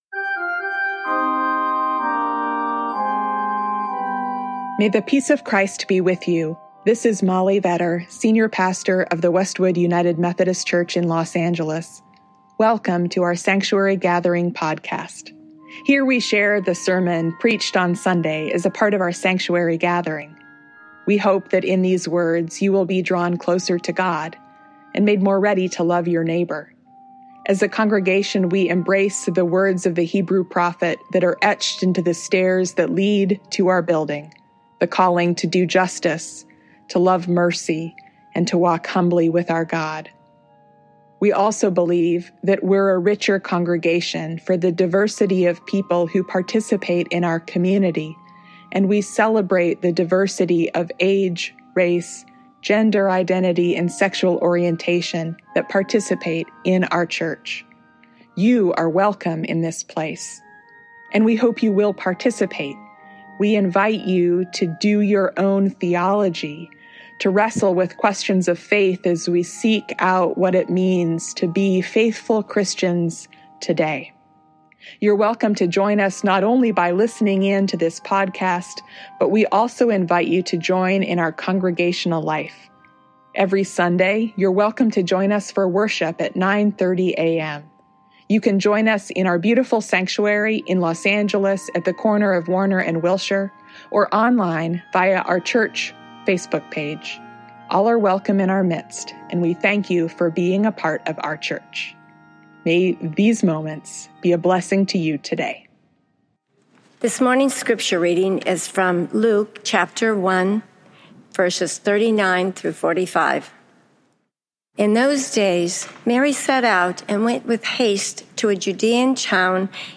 This Sunday, when the Fourth Sunday of Advent invites us to contemplate love, two of your pastors will preach together. In their collaborative message, they’ll explore the prophetic beauty of Mary’s song.